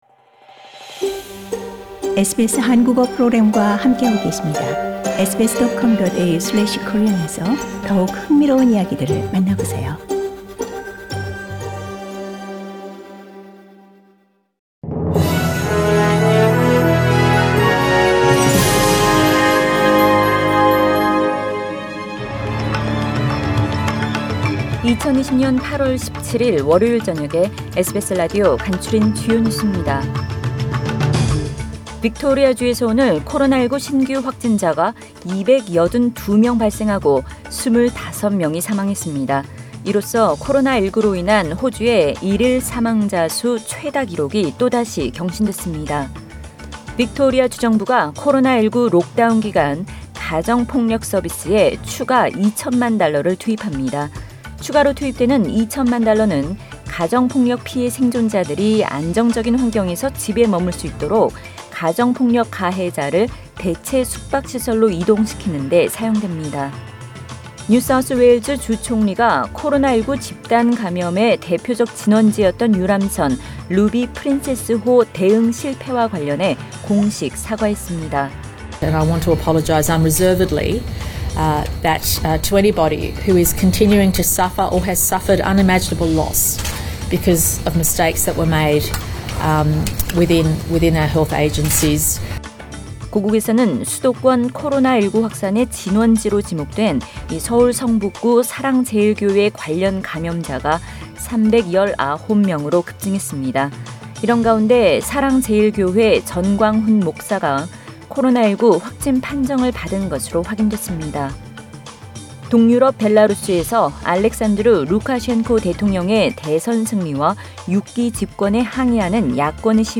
2020년 8월 17일 월요일 저녁의 SBS Radio 한국어 뉴스 간추린 주요 소식을 팟 캐스트를 통해 접하시기 바랍니다.